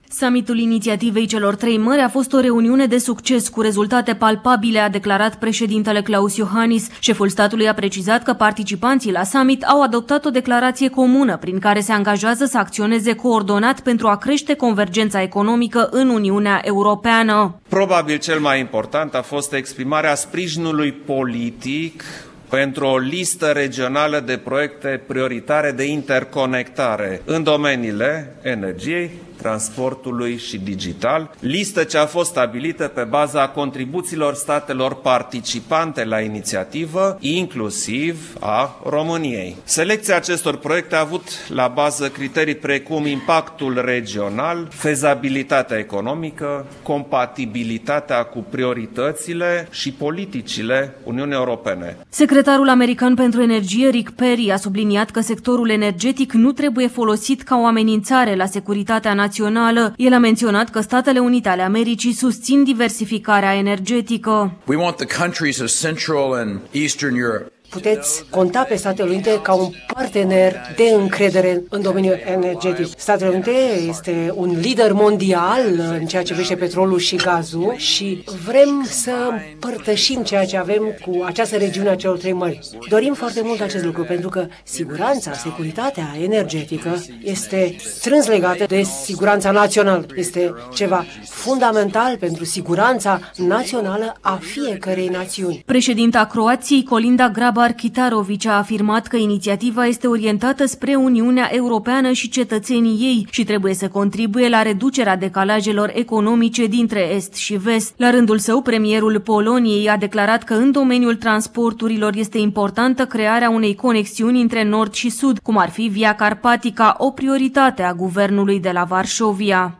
România se poate baza în domeniul energiei pe Statele Unite, afirmă secretarul american pentru energie, Rick Perry, care a participat, la Bucureşti, la Summitul Iniţiativei celor Trei Mări. Înaltul responsabil american a susţinut, la închierea reuniunii, o declaraţie de presă alături de preşedintele Klaus Iohannis, preşedinta Croaţiei, Kolinda Grabar-Kitarovic, şi premierul Republicii Polone, Mateusz Morawiecki.